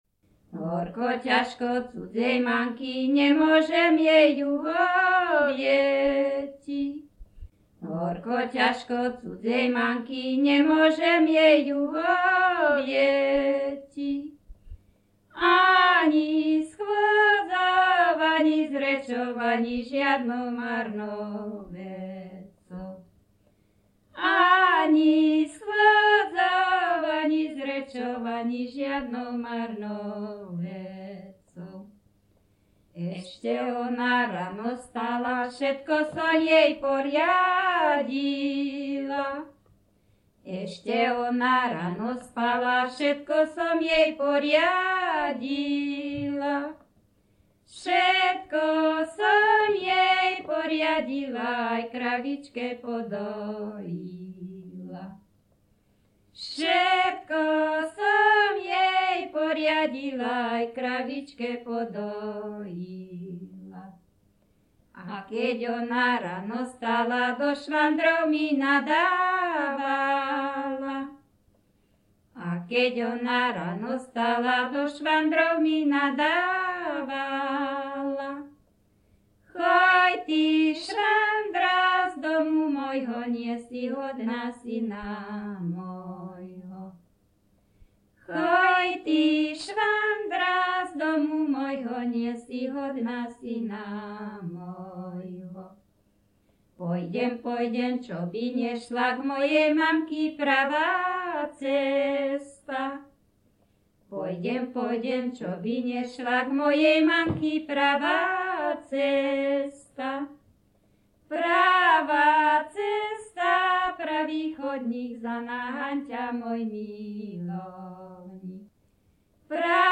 Descripton spev dvoch žien bez hudobného sprievodu
Place of capture Litava
Key words ľudová pieseň
čardáš